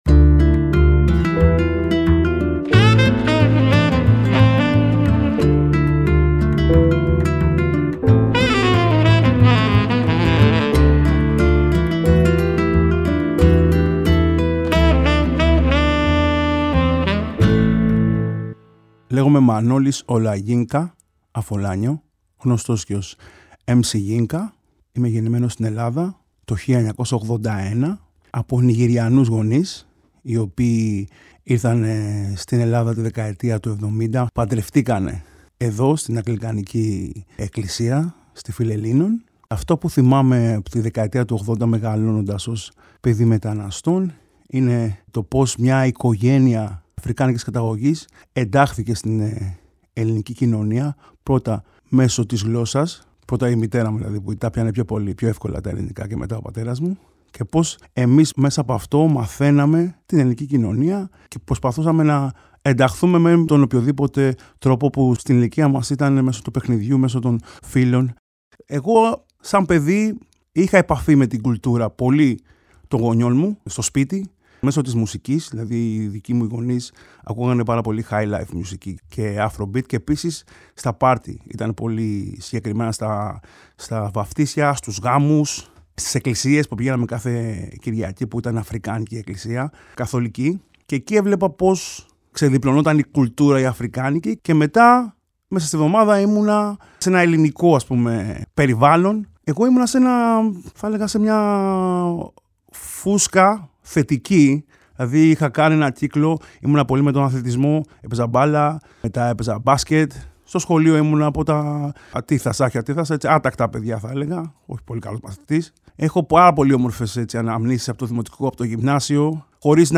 Στο πλαίσιο της συνεργασίας του Μουσείου Μπενάκη με την ΕΡΤ και το Κosmos 93,6, ηχογραφήθηκε, ειδικά για την έκθεση, μια σειρά προσωπικών αφηγήσεων μερικών από τους μουσικούς που συμμετέχουν στο ηχοτοπίο ΗΧΗΤΙΚΕΣ ΔΙΑΣΤΑΣΕΙΣ ΑΦΡΙΚΑΝΙΚΗΣ ΔΙΑΣΠΟΡΑΣ.